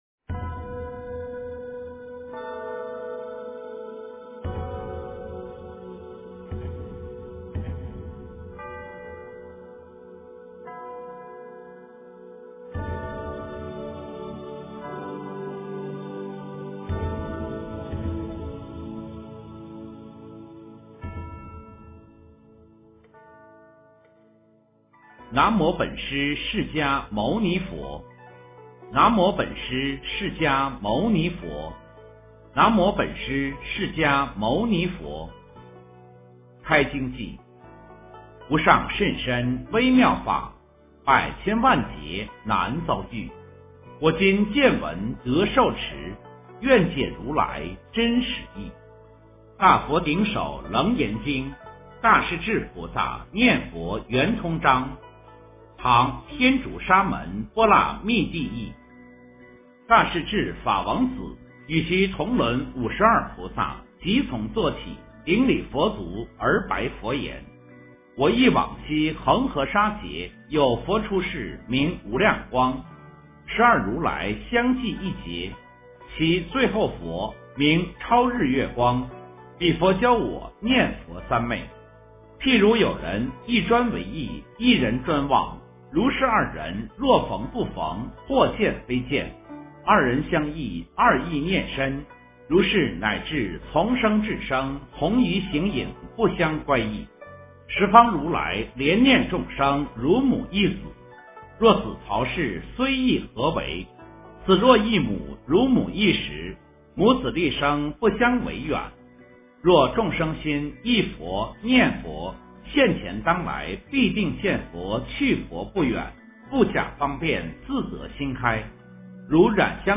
大势至菩萨念佛圆通章 - 诵经 - 云佛论坛